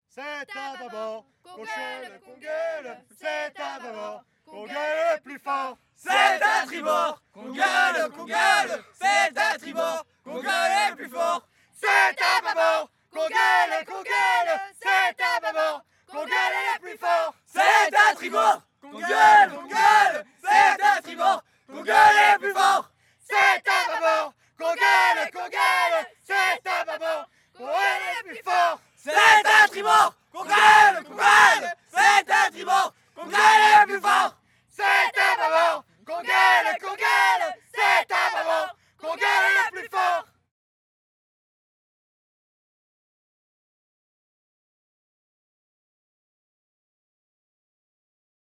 Genre : chant
Type : chant de mouvement de jeunesse
Interprète(s) : Les Scouts marins de Jambes
Lieu d'enregistrement : Jambes
Enregistrement réalisé dans le cadre de l'enquête Les mouvements de jeunesse en chansons.